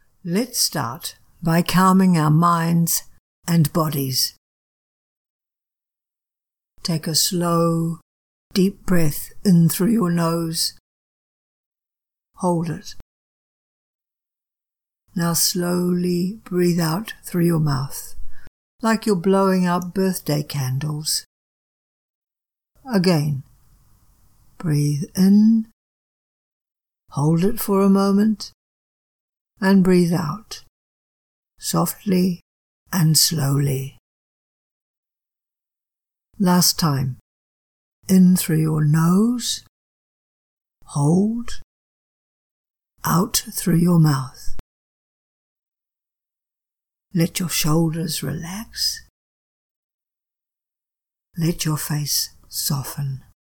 To give you an idea of the pace and pitch to use I recorded the first step of the teacher-led script for junior to middle school students.
First 50+ seconds of the visualization script read aloud.
guided-visualization-teacher-script-junior-middle-school.mp3